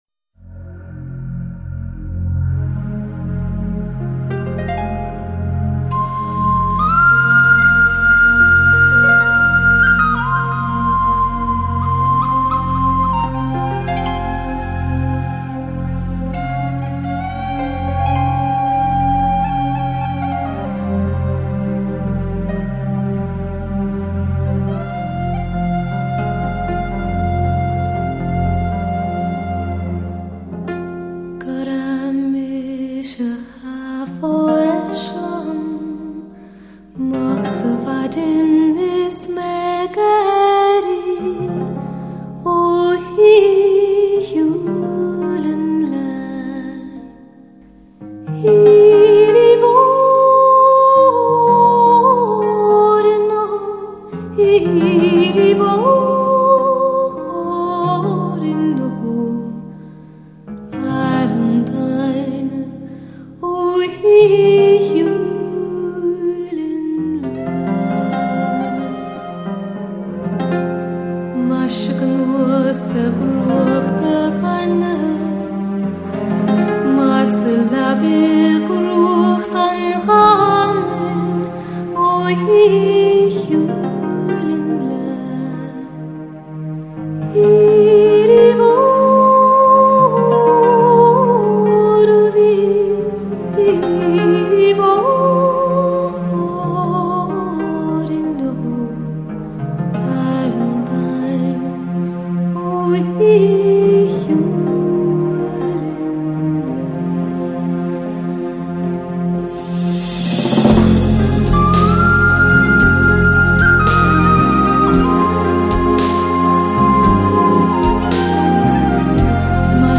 嗓音明亮照人，帶點迷人的甜味
背景人聲的和音亦顯得非常過癮和精彩